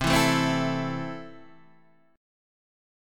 C6 chord